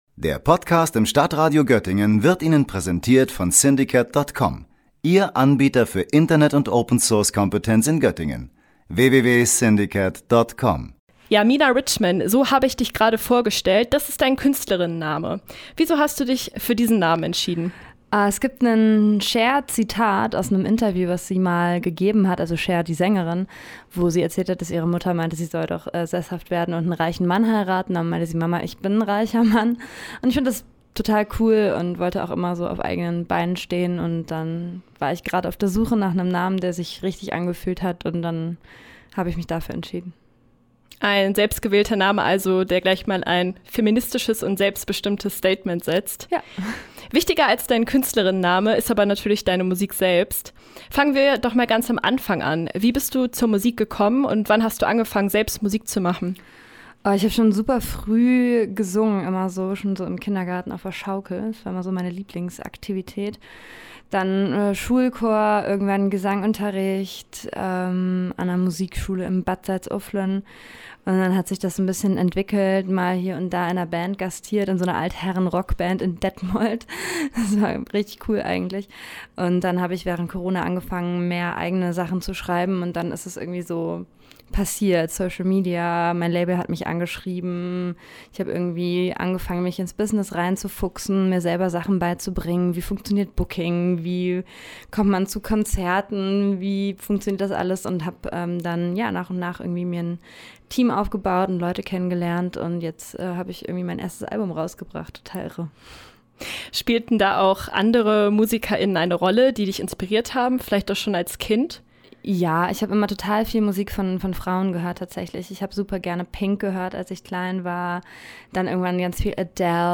Selbstreflektion als künstlerische Inspiration - Sängerin